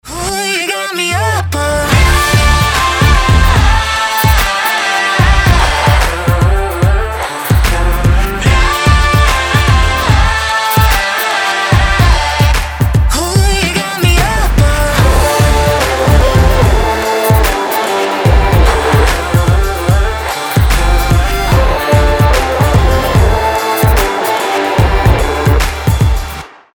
• Качество: 320, Stereo
поп
громкие
женский вокал